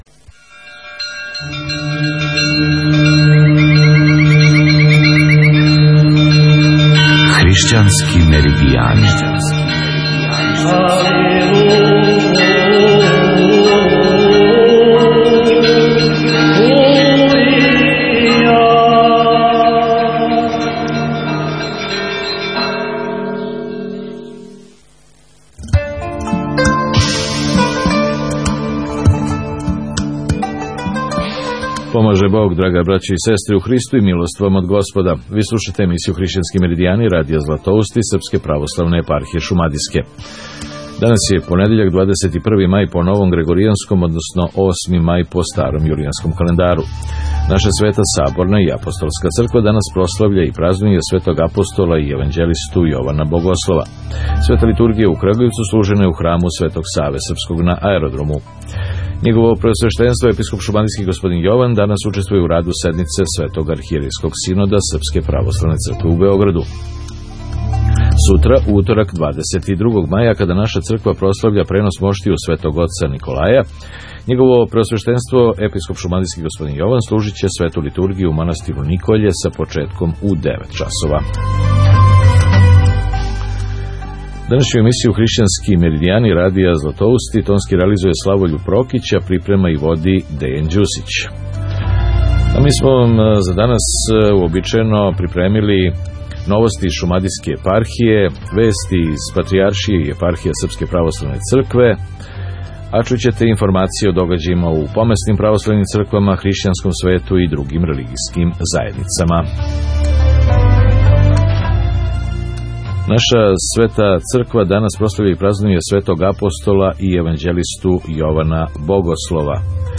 Поштовани слушаоци, слушате „ХРИШЋАНСКЕ МЕРИДИЈАНЕ” – издање вести из Српске православне цркве, наше православне Епархије шумадијске, помесних цркава и других верујућих заједница Радија „Златоусти” за понедељак, 21. мај по грегоријанском а 8. мај по јулианском календару.